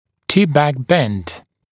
[‘tɪpbæk bend][‘типбэк бэнд]V-образный изгиб дуги для дистального наклона зуба